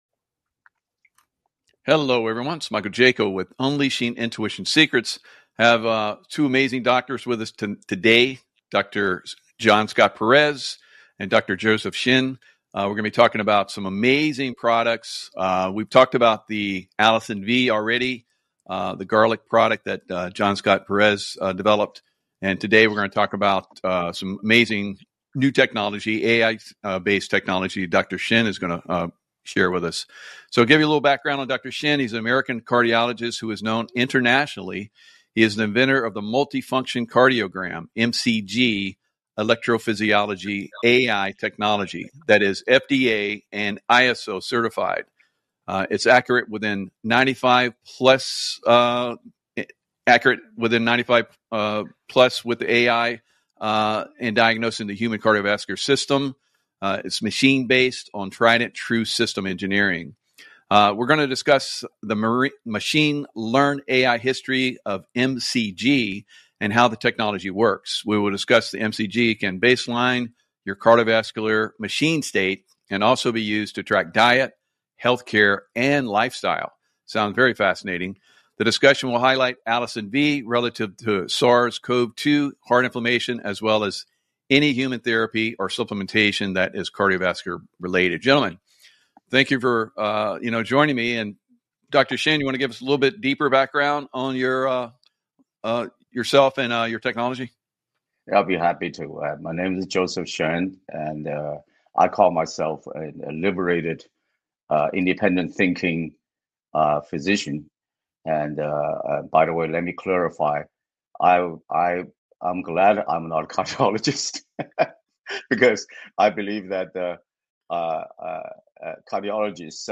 Spread the truth MP3 Audio 📰 Stay Informed with Sovereign Radio!